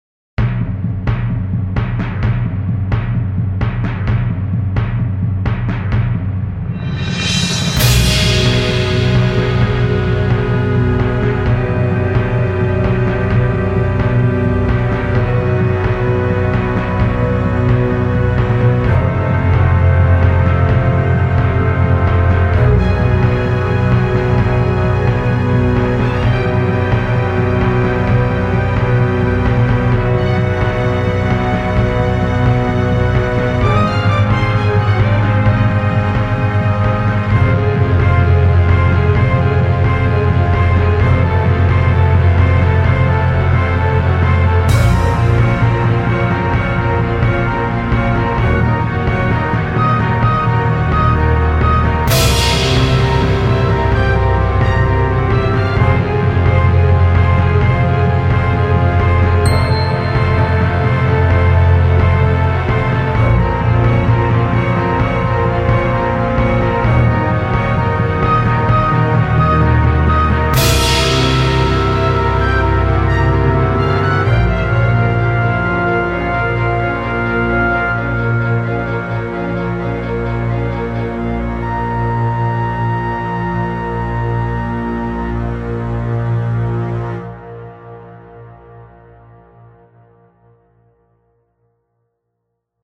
描述：电影|激越
Tag: 弦乐器 小号